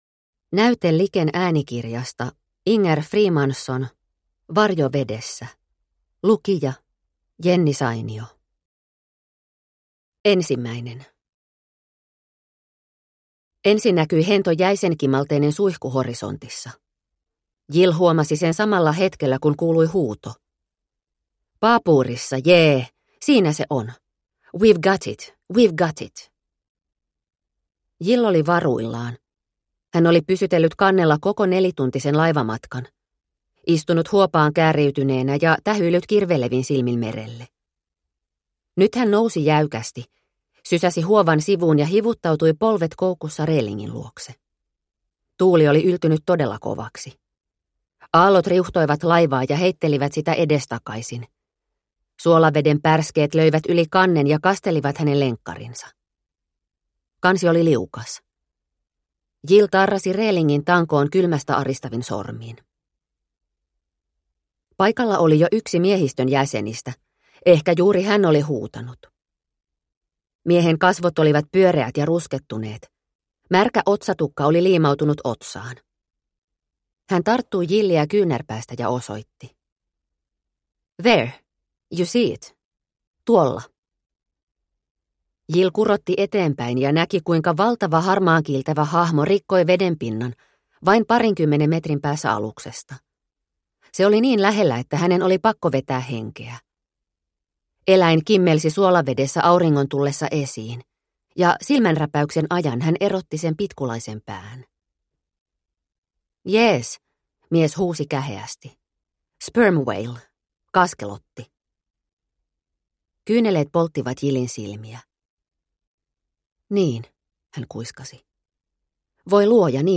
Varjo vedessä – Ljudbok – Laddas ner